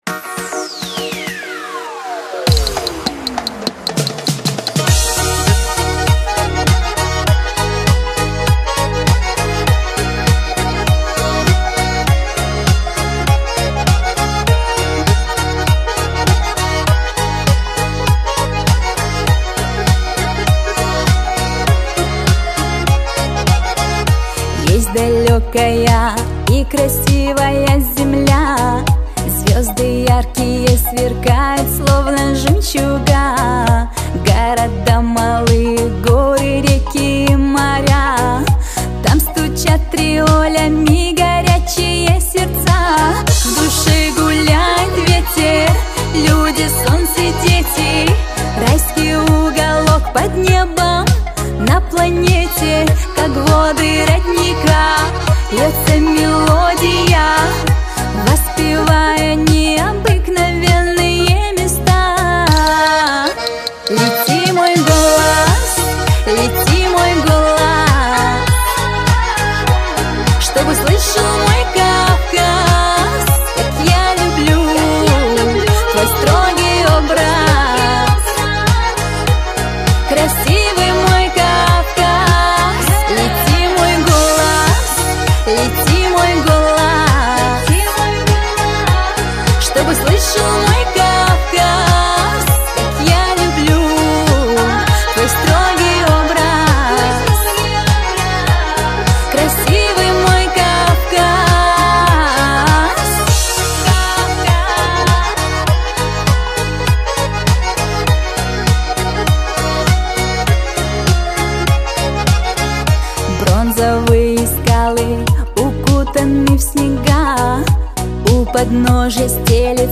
• Категория: Русская музыка